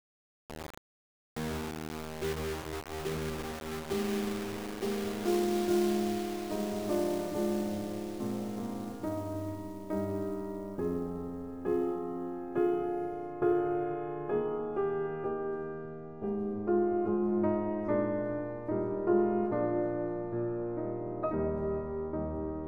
To examine the performance of the non-linear compression, I will use a real (piano music) example to showcase the effects of linear/non-linear dynamic compression.
To test this, I resampled the excerpt at a bit depth that is increasing over time, starting with 4 bit (everything below is rather painful).
This is clearly not good enough for human ears.
However, the decisive quality increase seems to happen between 6 and 8 bit.